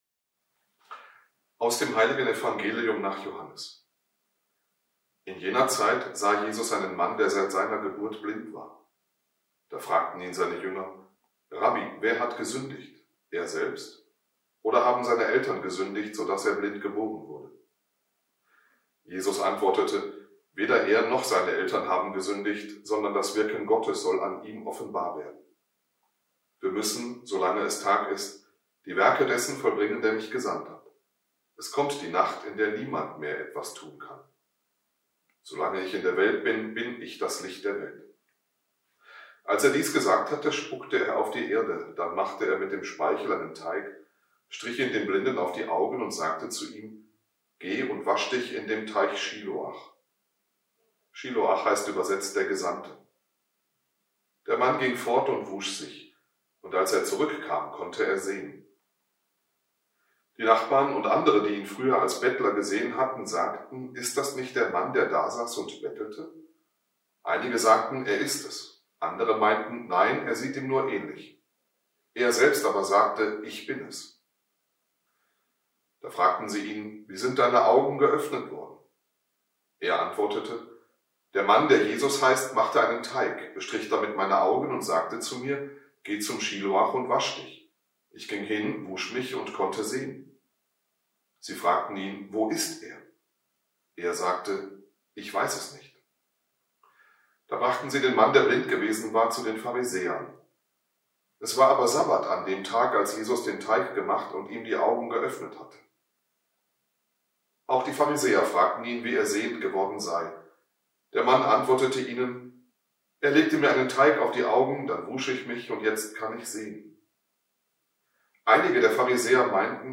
Impuls zum 4. Fastensonntag